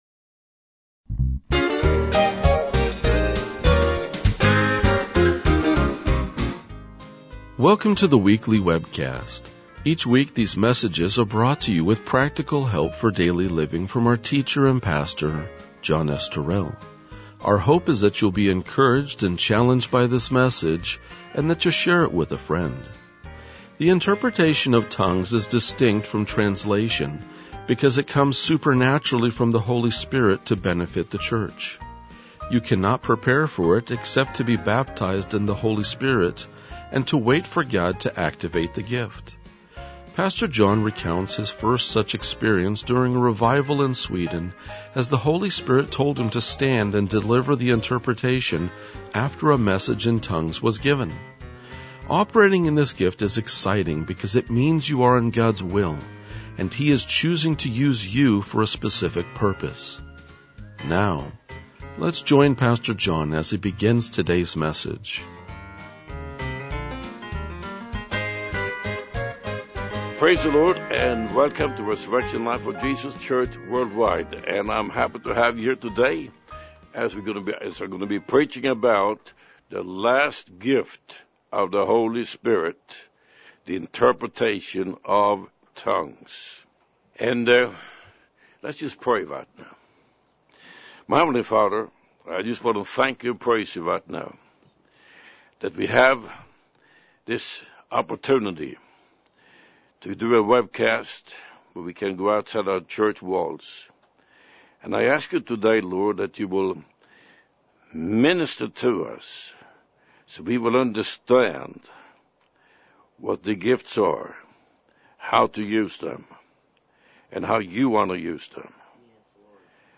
RLJ-2030-Sermon.mp3